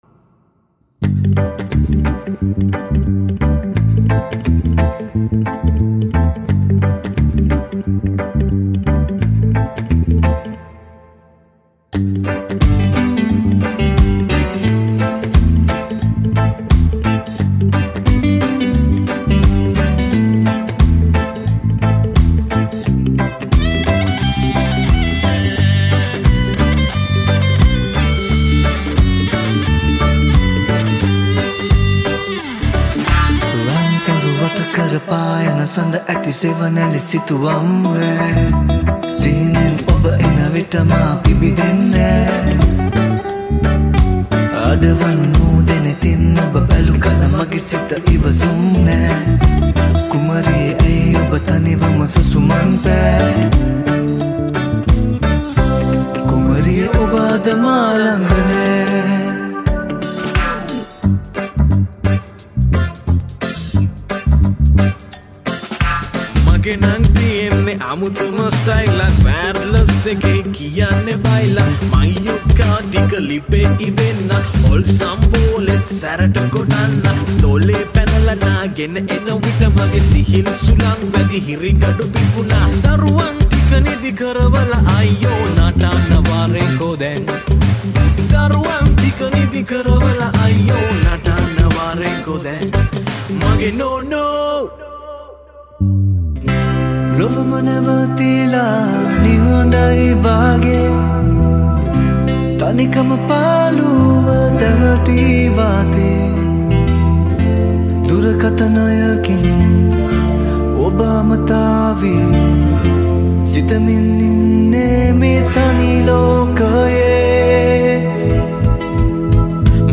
Covers
Vocals
Guitars
Bass guitar